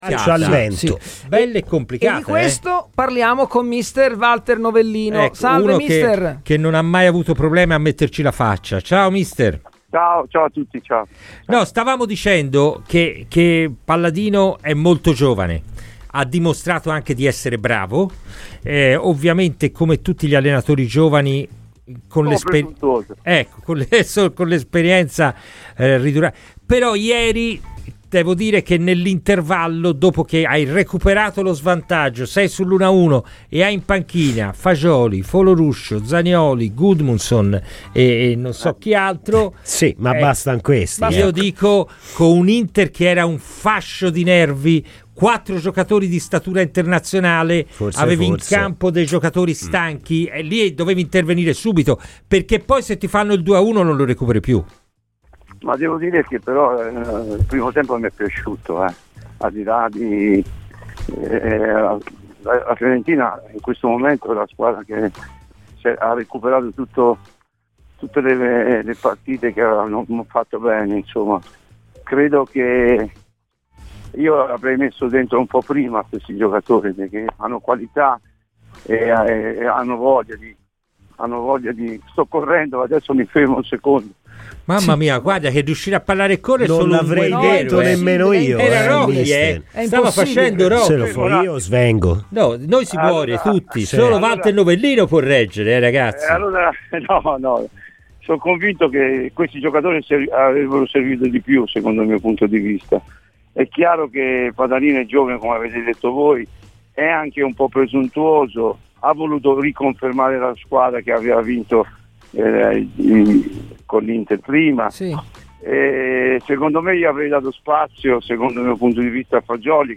Ai microfoni di Radio FirenzeViola, durante "Palla al Centro", l'ex allenatore Walter Novellino ha parlato così a seguito della sconfitta della Fiorentina a San Siro contro l'Inter: "Il primo tempo mi è piaciuto.